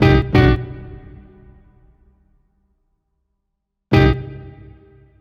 Back Alley Cat (Tone Hit 01).wav